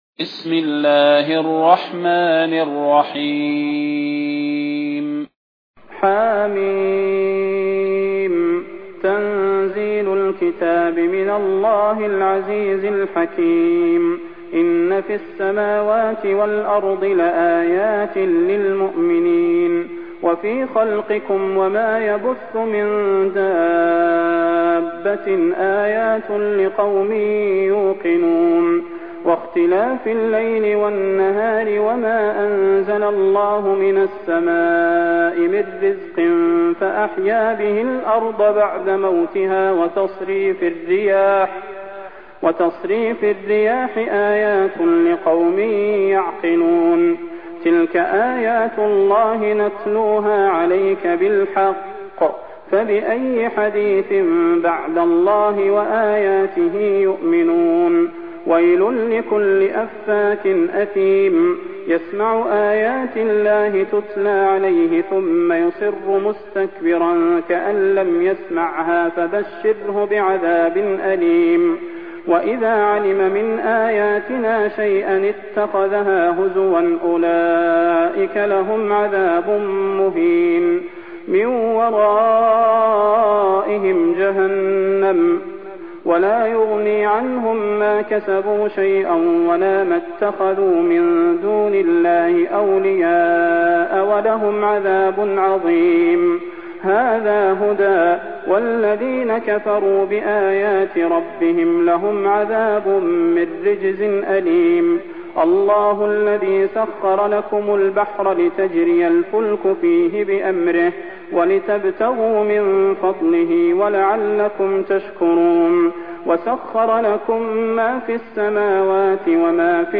المكان: المسجد النبوي الشيخ: فضيلة الشيخ د. صلاح بن محمد البدير فضيلة الشيخ د. صلاح بن محمد البدير الجاثية